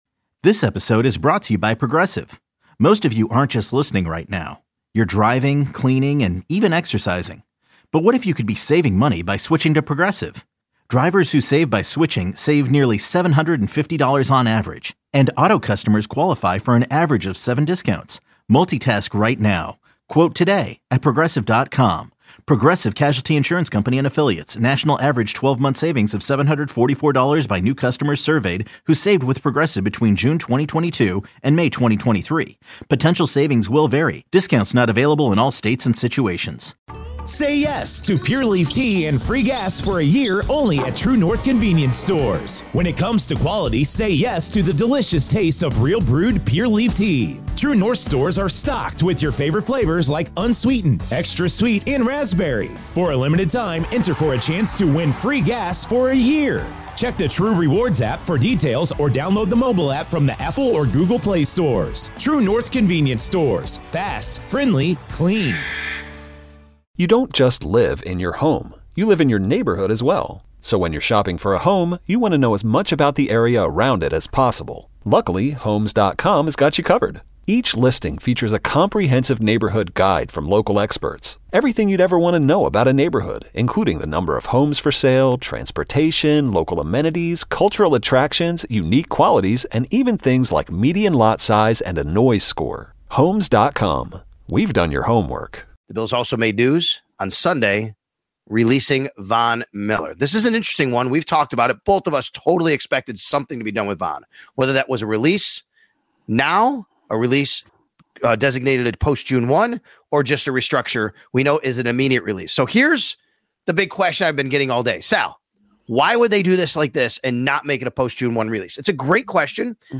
THE EXTRA POINT is designed for full engagement with bills fans as the two hosts open the phones, texts sand tweets to connect with the Bills mafia on everything Bills!